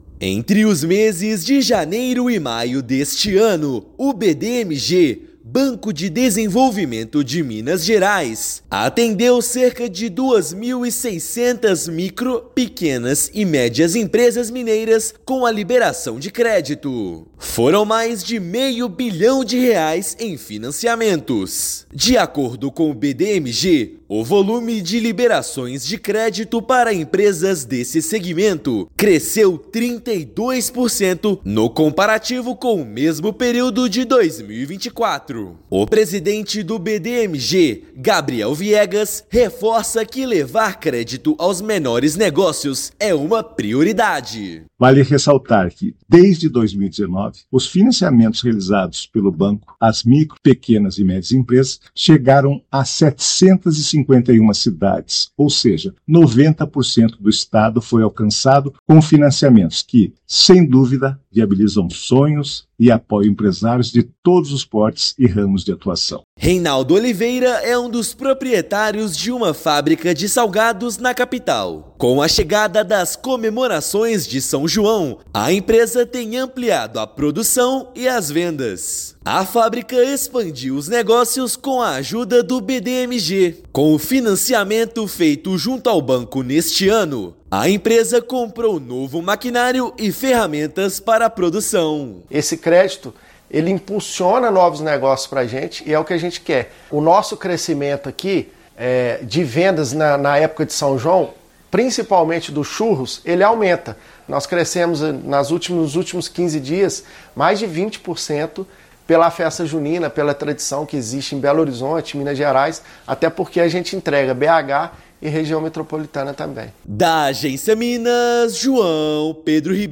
[RÁDIO] Empresários mineiros buscam mais crédito no BDMG em 2025 e planejam aumentar faturamento com as festas juninas
No mês Internacional das Micro, Pequenas e Médias empresas, balanço do banco mostra mais de meio bilhão em financiamentos. Ouça matéria de rádio.